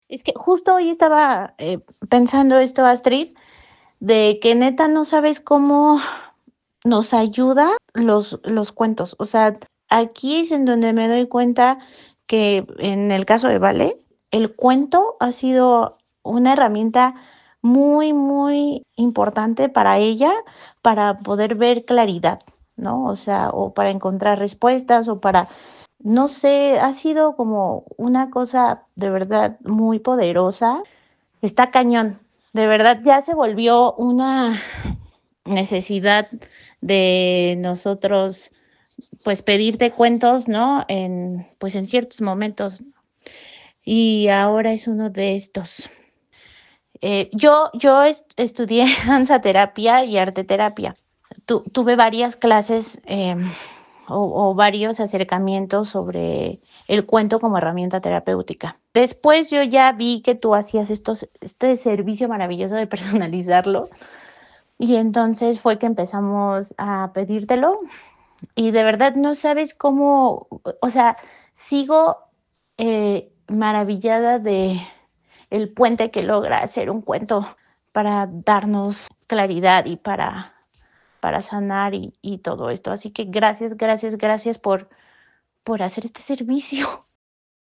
Testimonio Audiocuento